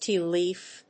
アクセントtéa lèaf